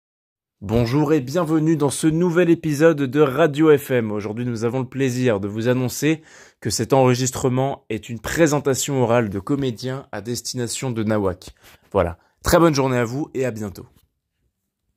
Rôle présentateur radio